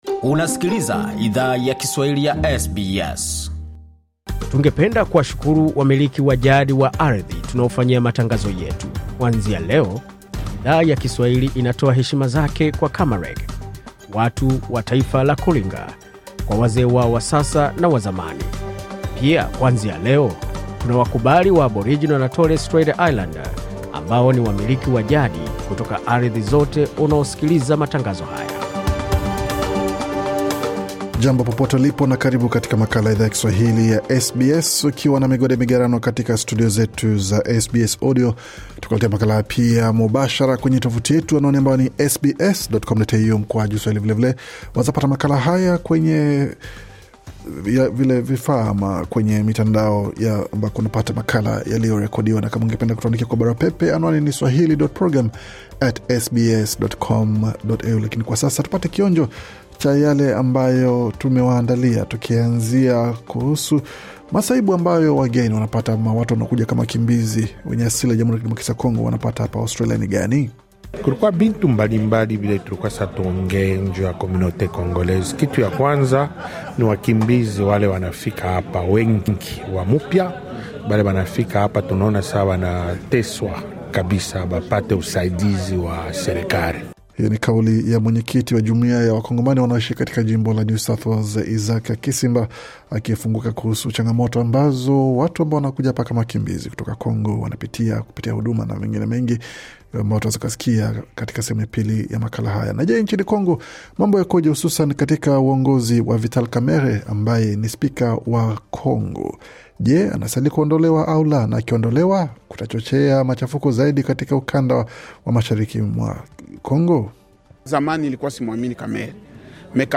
Taarifa ya Habari 23 Septemba 2025